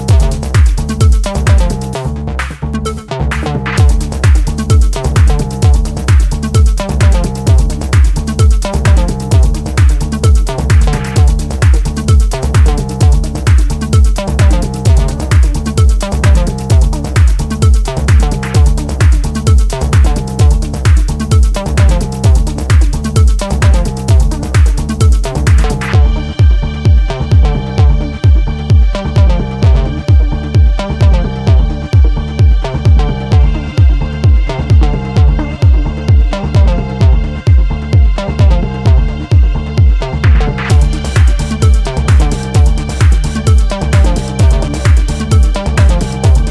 hot house bomb
Two raw, distinctive, ultra-dry dancefloor slammers